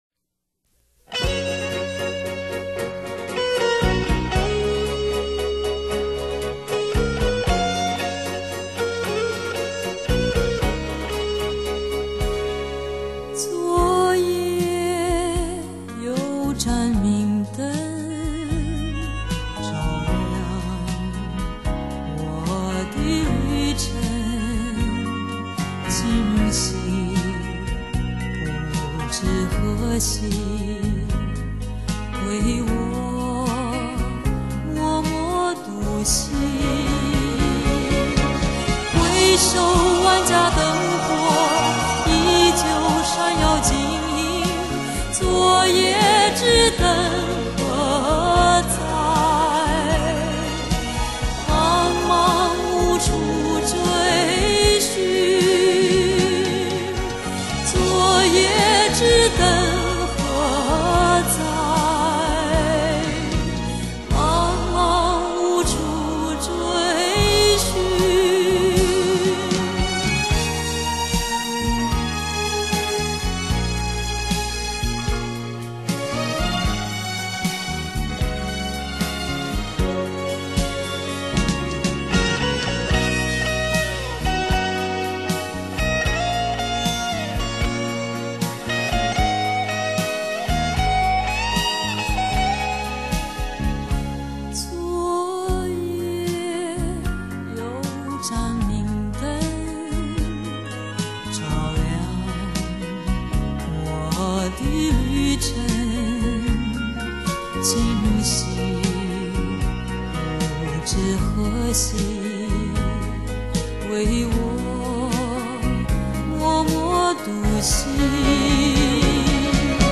时光流逝，她的歌声明净醇厚不变；爱情老了，她的微笑沉静温暖不老……